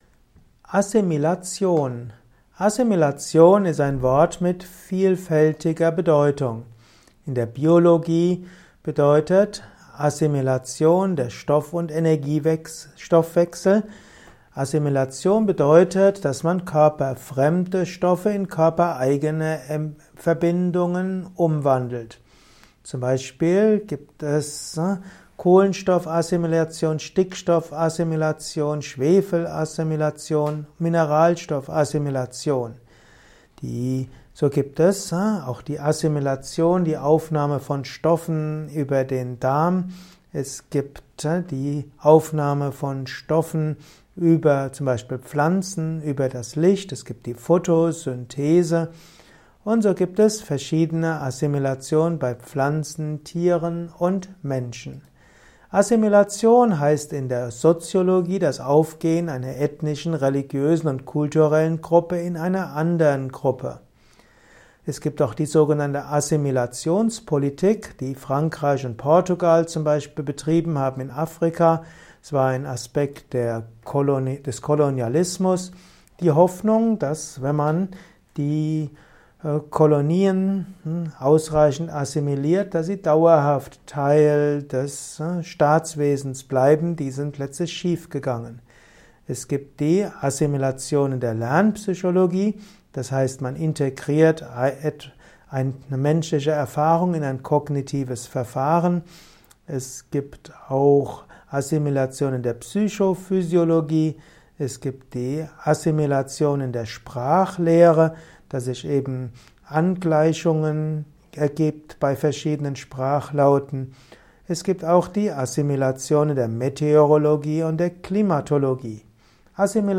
Kompakte Informationen zur Assimilation in diesem Kurzvortrag